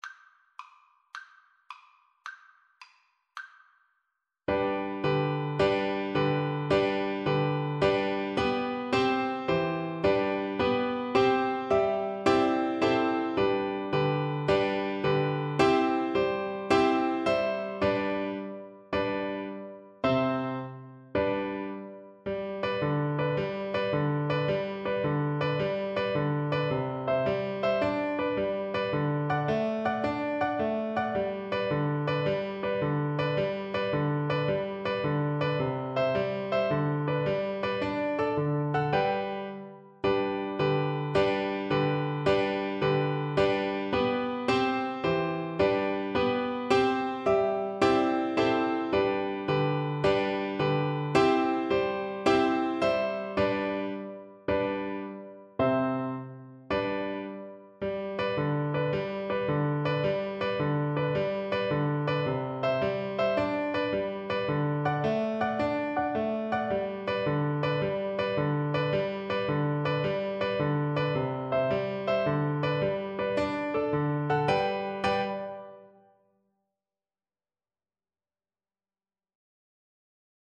Play (or use space bar on your keyboard) Pause Music Playalong - Piano Accompaniment Playalong Band Accompaniment not yet available transpose reset tempo print settings full screen
Flute
G major (Sounding Pitch) (View more G major Music for Flute )
Allegro moderato .=c.108 (View more music marked Allegro)
6/8 (View more 6/8 Music)
Traditional (View more Traditional Flute Music)